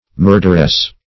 Search Result for " murderess" : Wordnet 3.0 NOUN (1) 1. a woman murderer ; The Collaborative International Dictionary of English v.0.48: Murderess \Mur"der*ess\, n. A woman who commits murder.
murderess.mp3